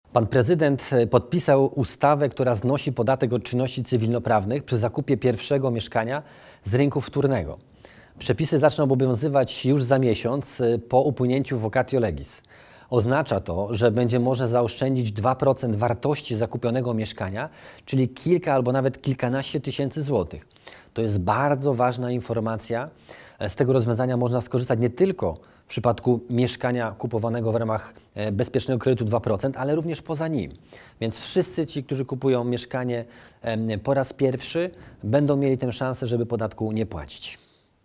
Wypowiedź ministra Waldemara Budy